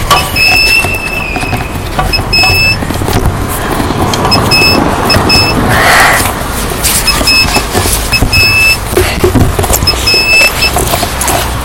In via Michelangelo, di fronte al civico 5, c’è un parcheggio, d’avanti a tutti i parcheggi c’è una colonnina che fa questo suono quando la schiacci!